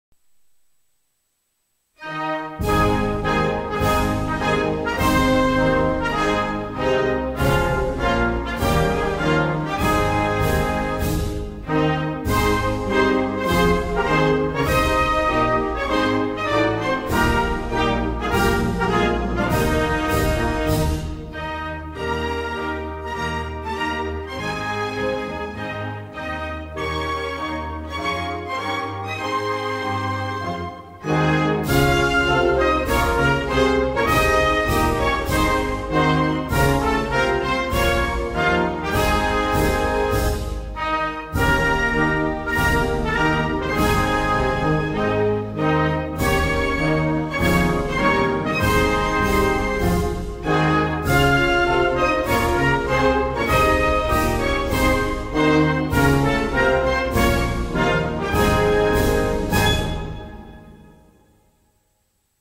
торжественная мелодия